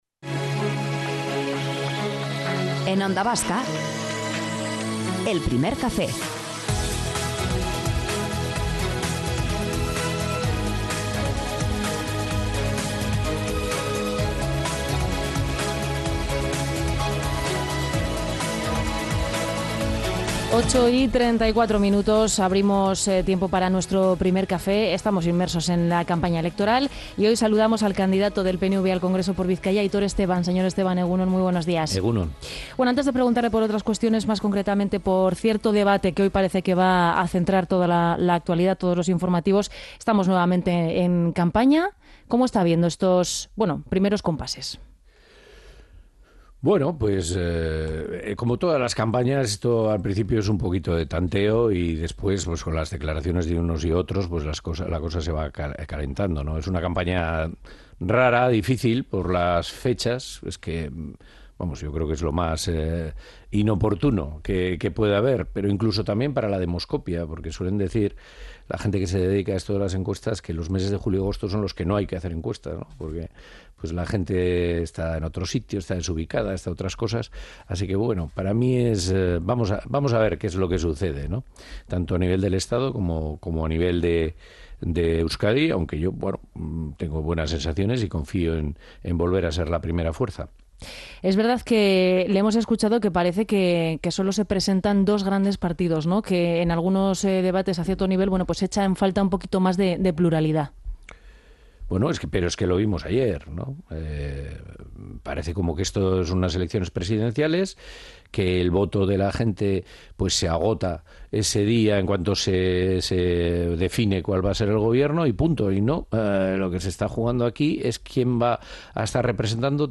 Elegimos la composición de Congreso y Senado y en esta entrevista si algo deja claro el candidato del PNV por Bizkaia es que es necesario que alguien represente a la sociedad vasca y sus problemas en Madrid.